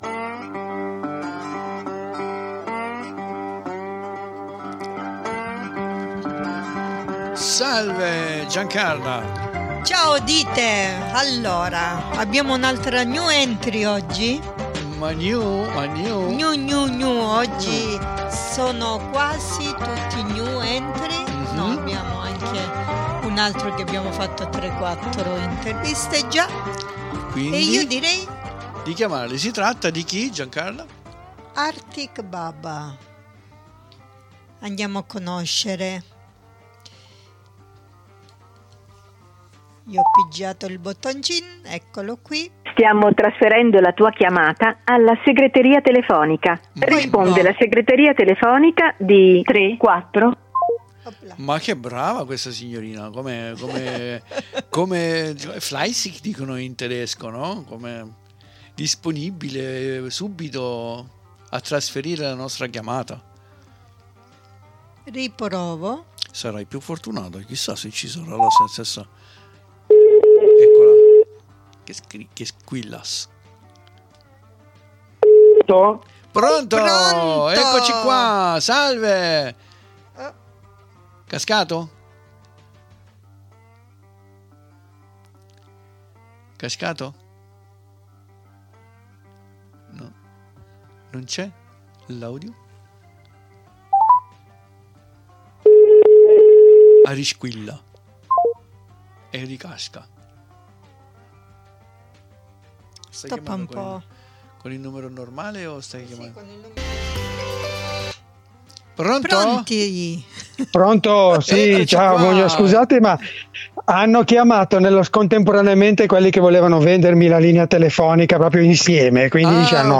95777_INTERVISTA_Artic_Baba.mp3